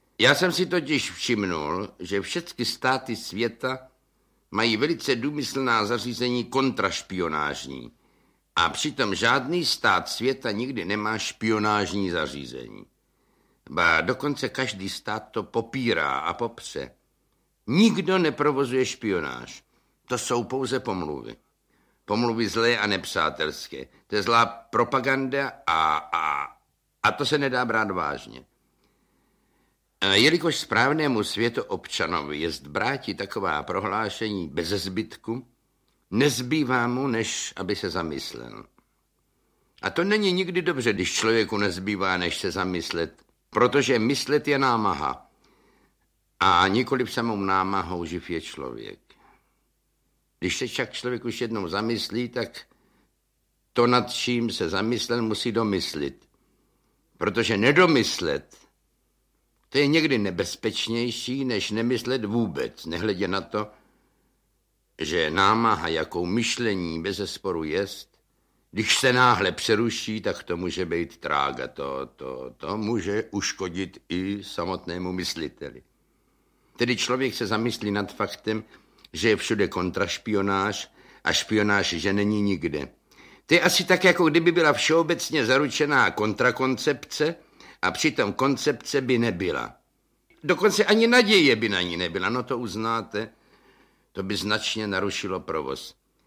Audio kniha
Ukázka z knihy
• InterpretJan Werich